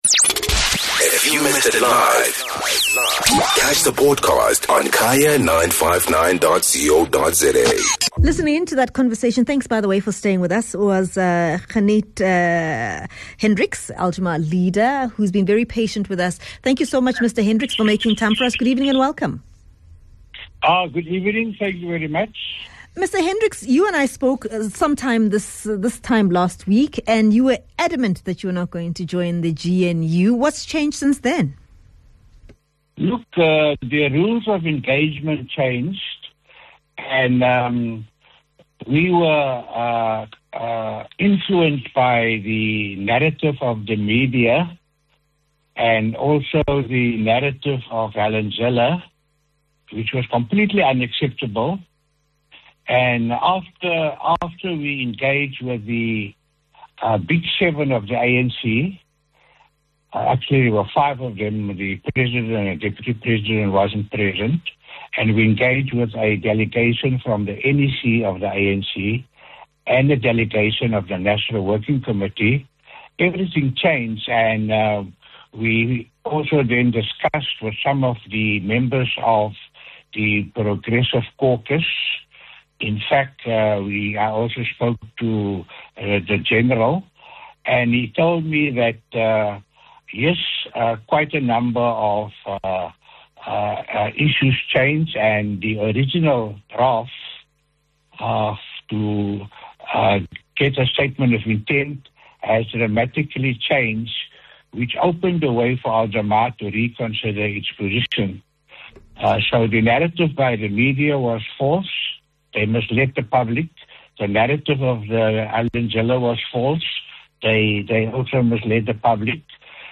Guest: Ganief Hendricks, Al Jama-ah Political Party Leader